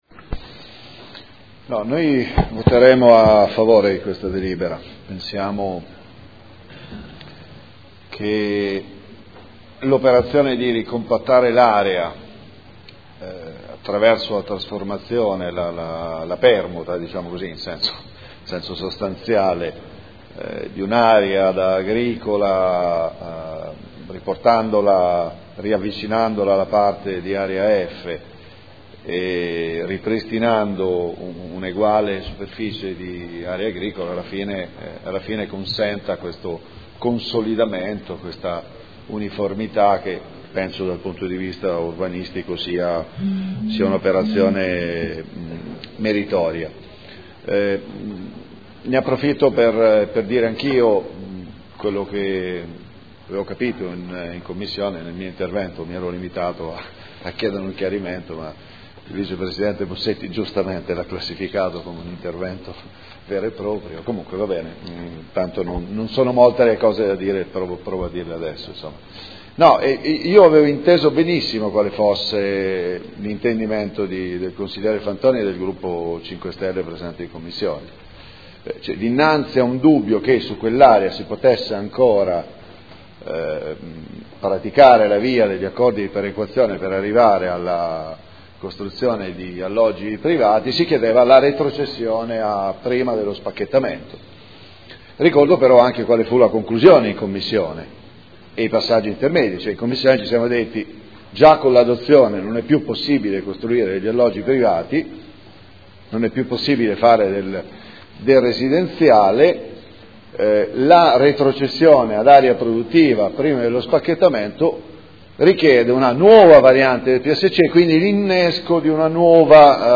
Seduta del 30/03/2015. Proposta di deliberazione: Variante al Piano Strutturale Comunale (PSC) – Area ubicata tra Tangenziale, strada Ponte Alto e strabello Anesino – Zona elementare n. 2050 Area 01 – Controdeduzioni alle osservazioni e approvazione ai sensi dell’art. 32 della L.R. 20/2000 e s.m.i. Dichiarazioni di voto